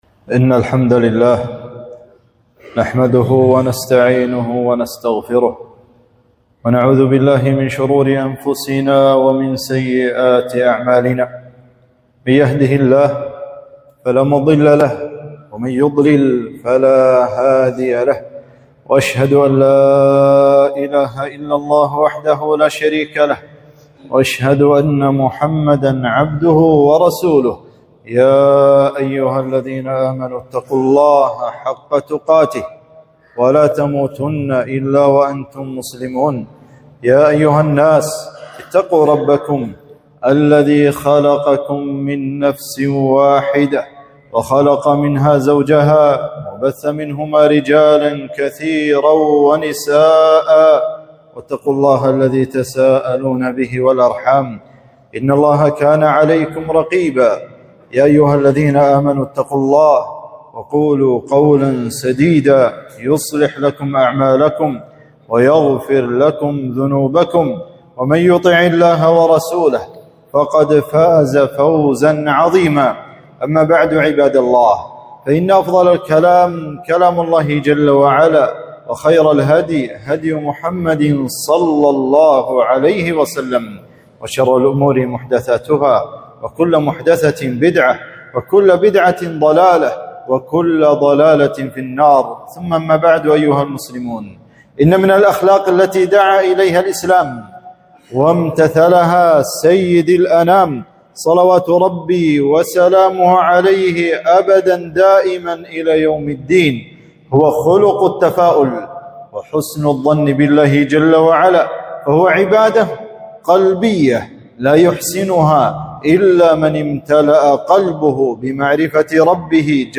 خطبة - الفأل الحسن